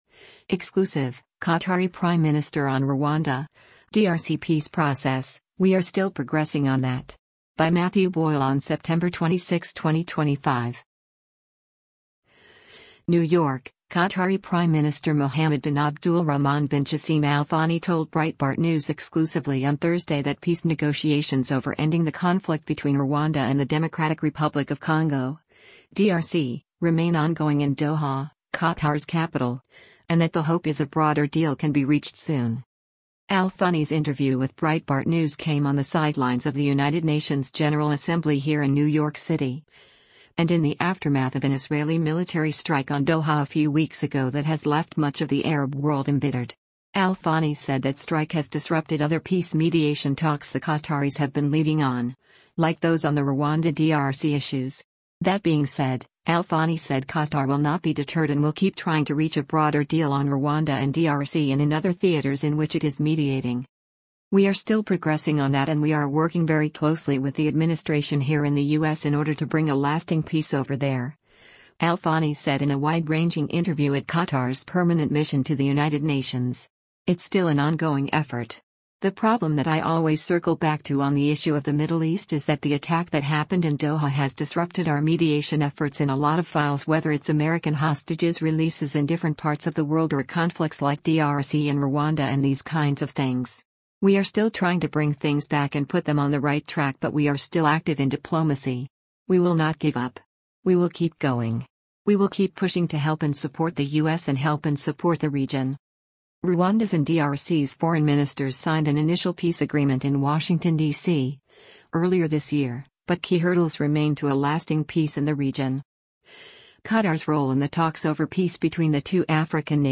Al Thani’s interview with Breitbart News came on the sidelines of the United Nations General Assembly here in New York City, and in the aftermath of an Israeli military strike on Doha a few weeks ago that has left much of the Arab world embittered.